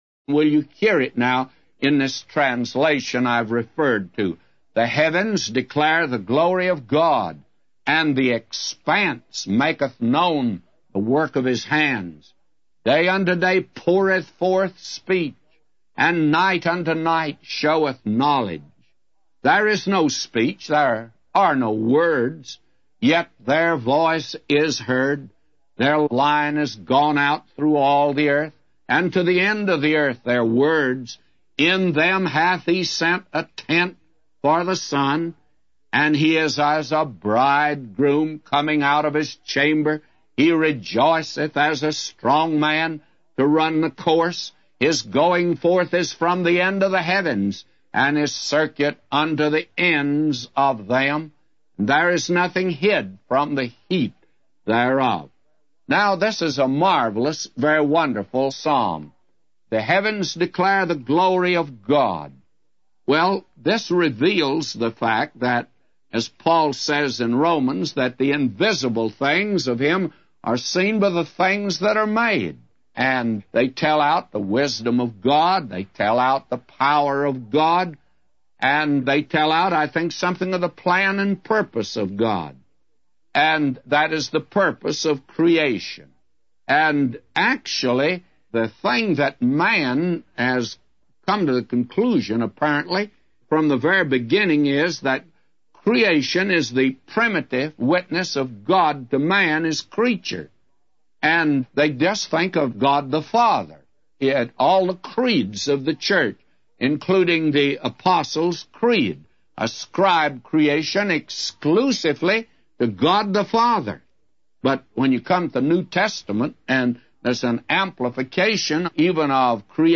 A Commentary By J Vernon MCgee For Psalms 19:1-999